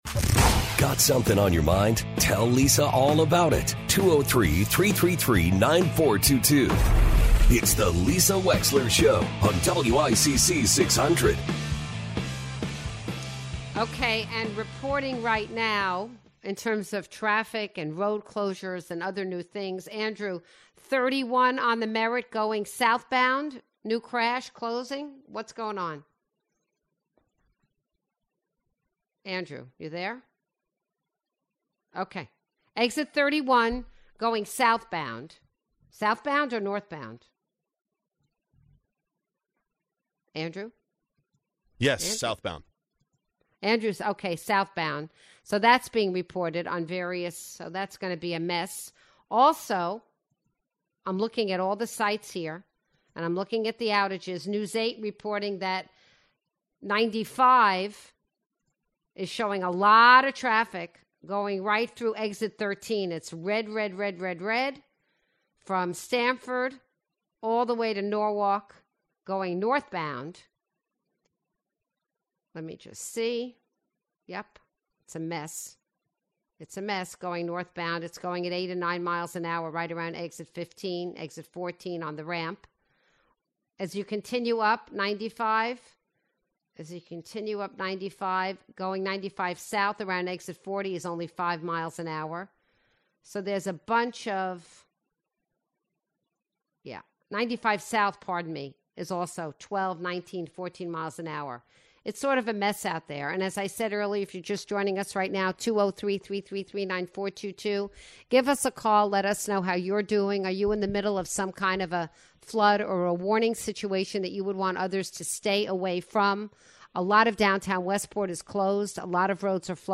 Fairfield State Representative Cristin McCarthy-Vahey joins the show to discuss environmental issues facing CT.